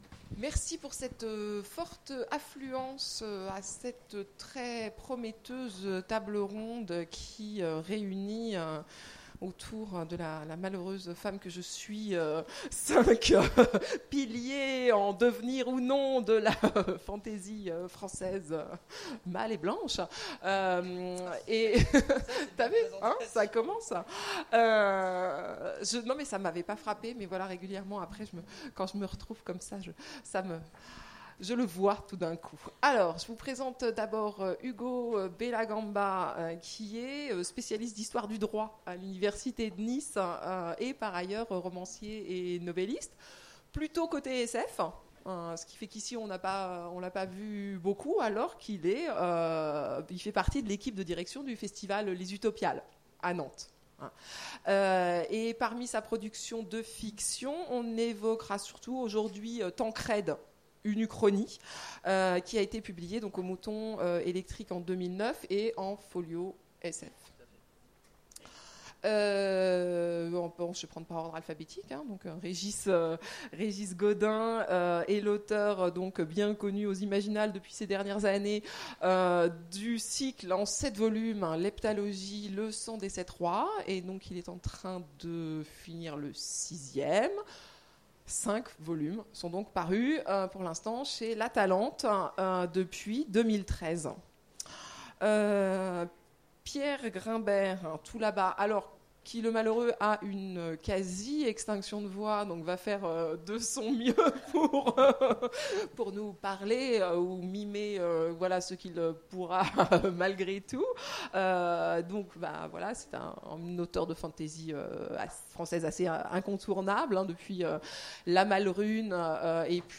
Imaginales 2015 : Conférence Rois et royaumes...